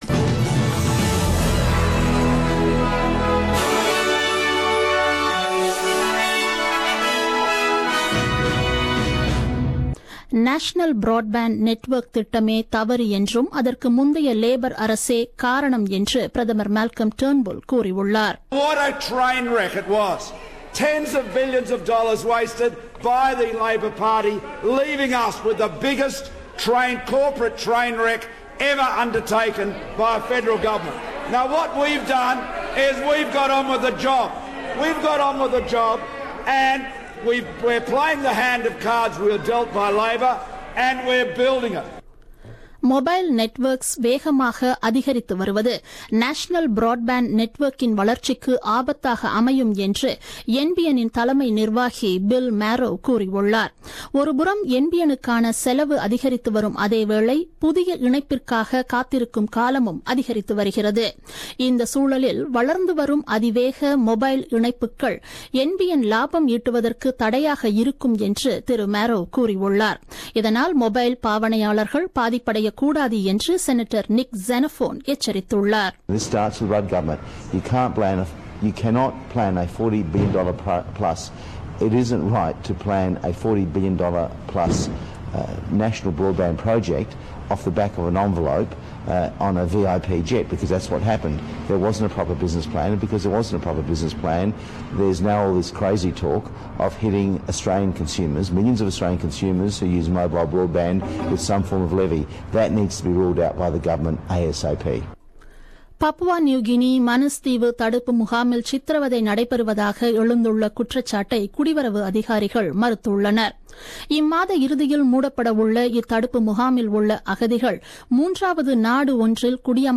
The news bulletin aired on 23 October 2017 at 8pm.